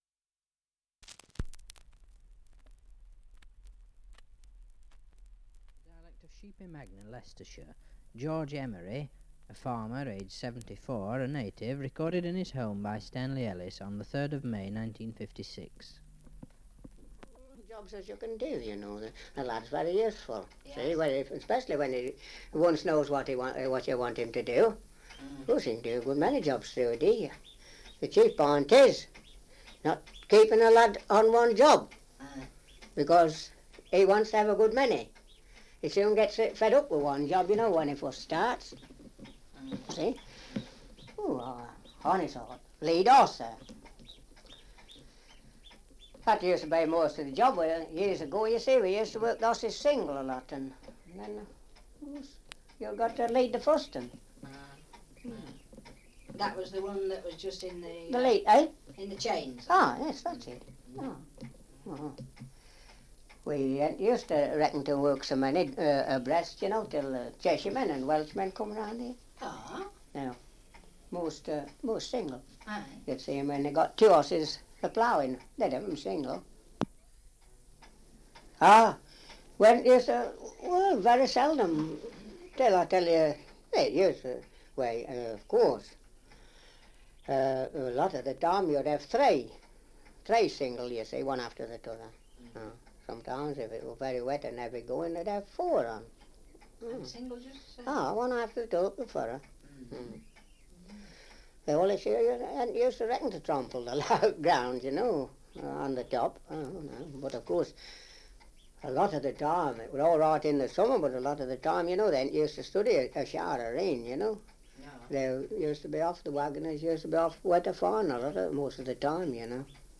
Survey of English Dialects recording in Sheepy Magna, Leicestershire
78 r.p.m., cellulose nitrate on aluminium